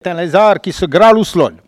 Localisation Soullans ( Plus d'informations sur Wikipedia ) Vendée
Catégorie Locution